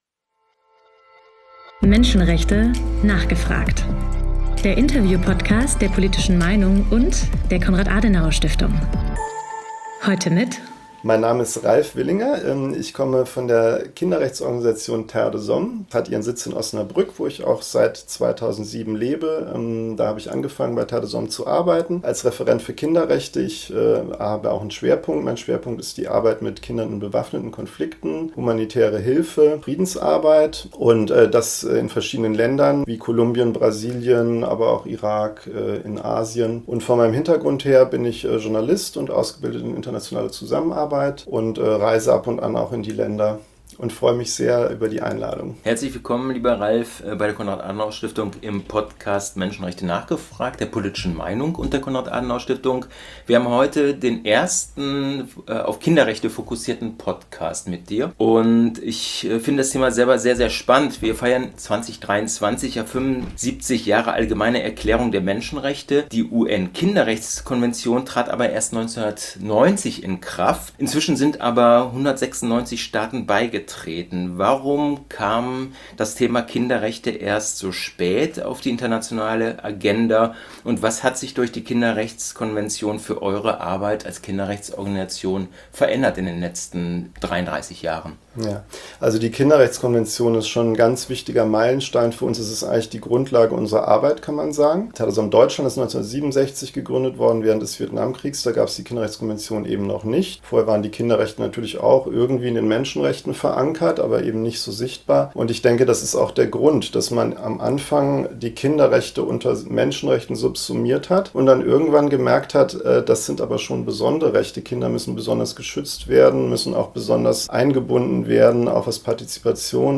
Menschenrechte: nachgefragt! - Der Interview-Podcast rund ums Thema Menschenrechte